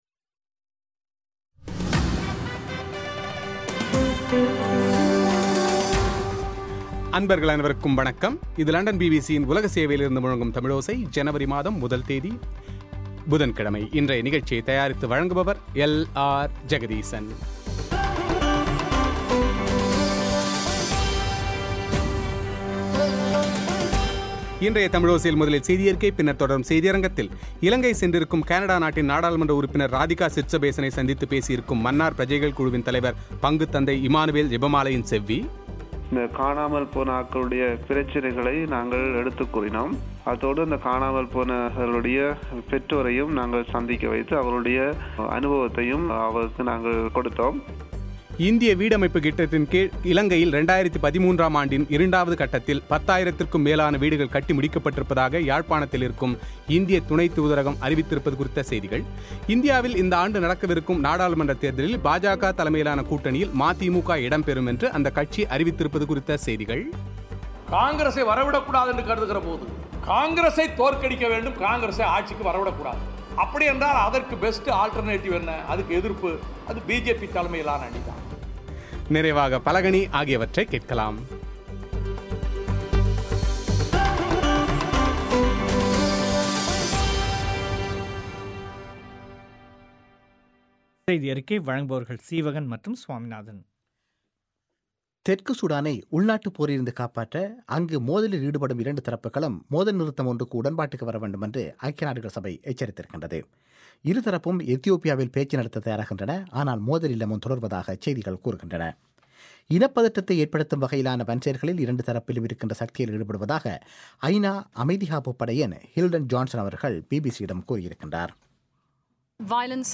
செவ்வி;